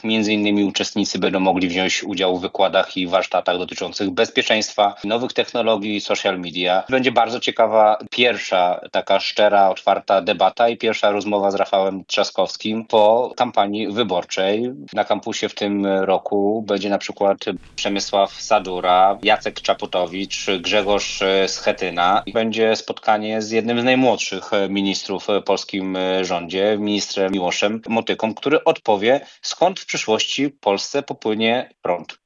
O szczegółach mówi Patryk Jaskulski, zachodniopomorski poseł Koalicji Obywatelskiej.